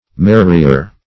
Marrier \Mar"ri*er\